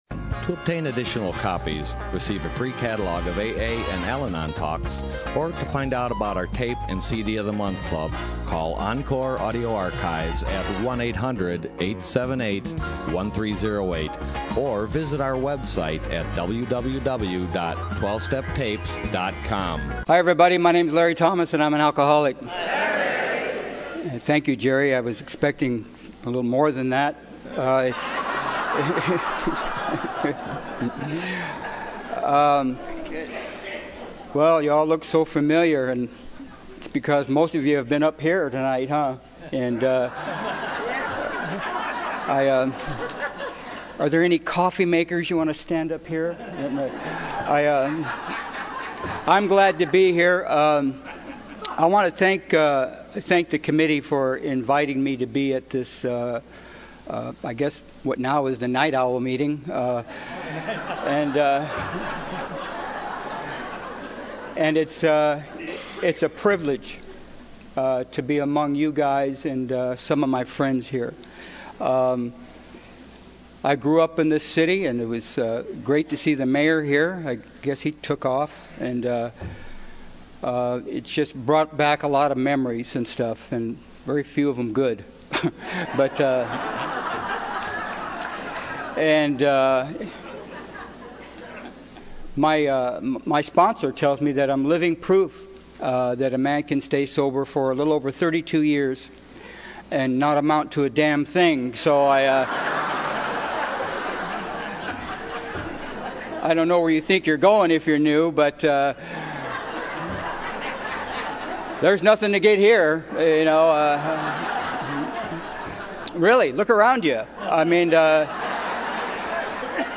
SOUTHBAY ROUNDUP 2014